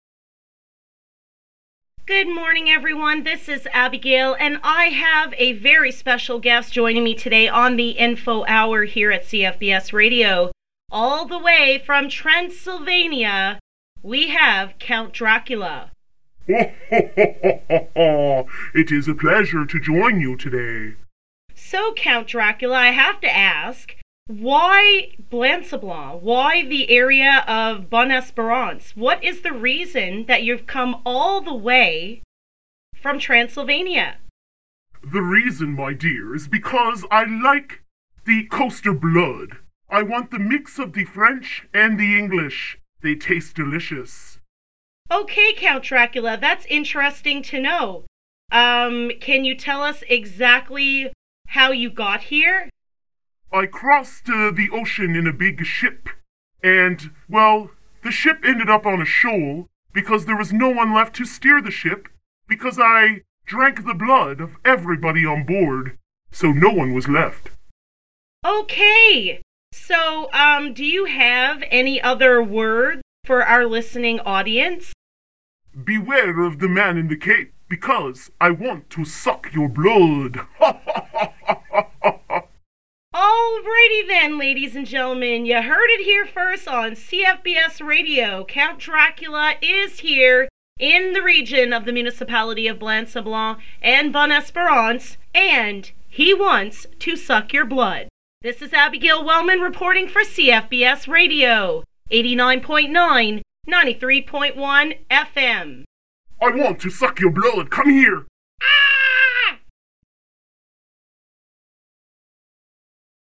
CFBS had a surprise visitor today, Count Dracula appeared at our doorstep requesting an interview, and in true coaster fashion, we welcomed him in.